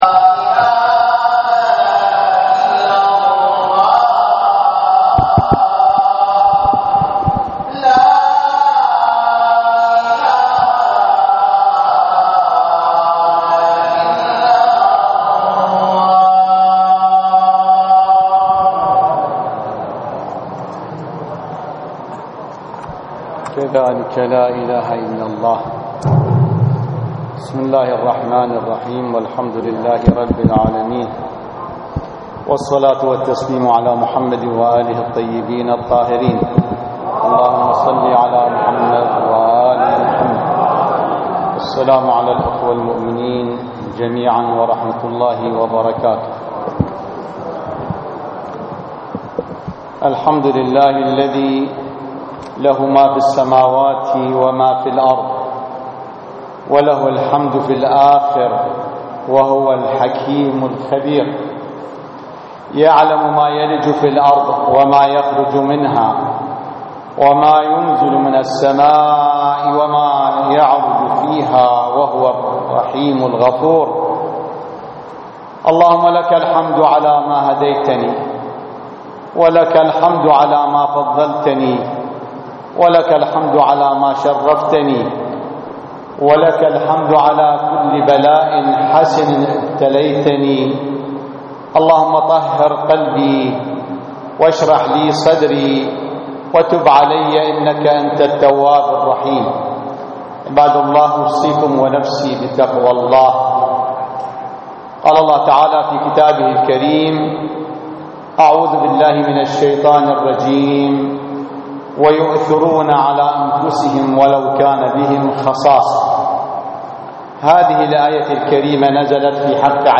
صلاة الجمعة في مدينة الناصرية - تقرير صوتي مصور -
للاستماع الى خطبة الجمعة الرجاء اضغط هنا